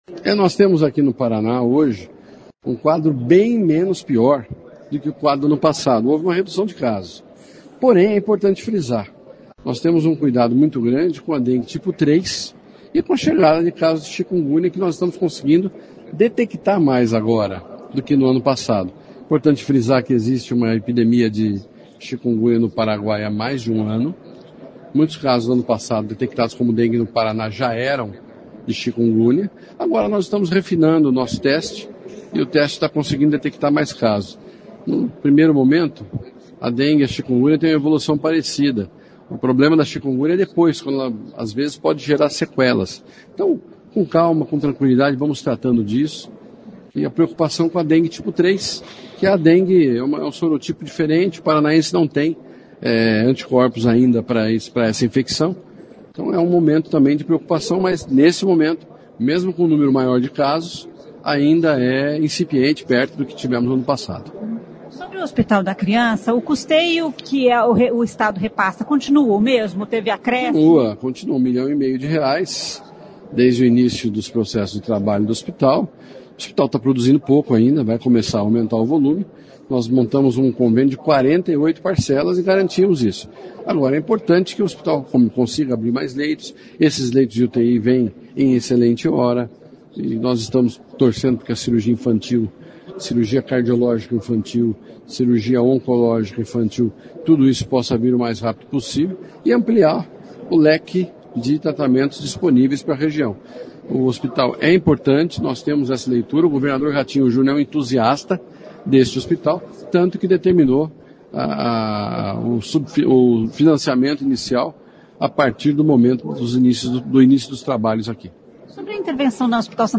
O secretário de Saúde do Paraná, Beto Preto, que participou nesta manhã da inauguração da UTI do Hospital da Criança, falou sobre a situação da dengue e chikungunya no Paraná. Segundo o secretário, enquanto os casos de dengue estão caindo, os de chikungunya aumentaram.